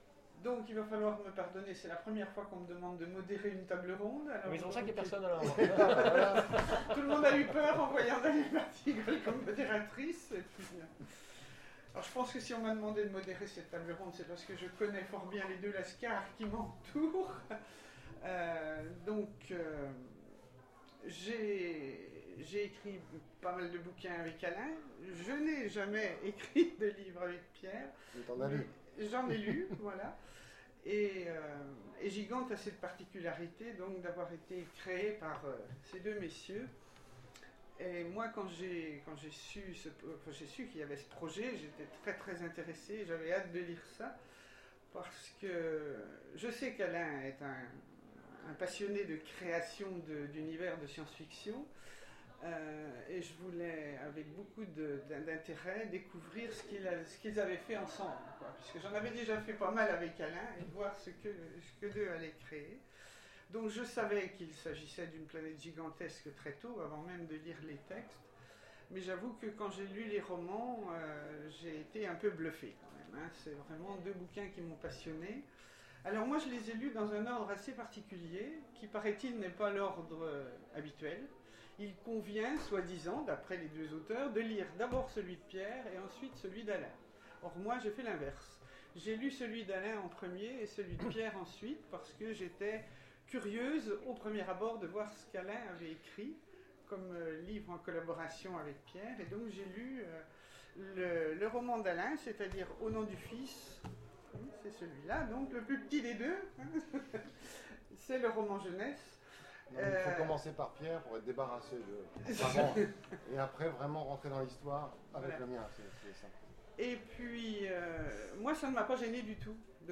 Les Oniriques 2015 : Table ronde Gigante à la loupe